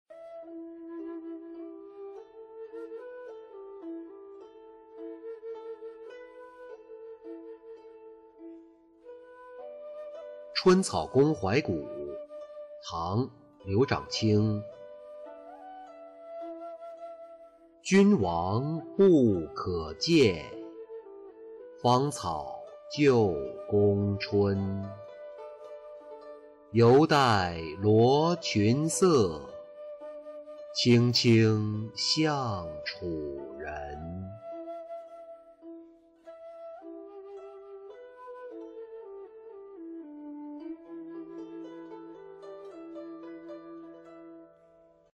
春草宫怀古-音频朗读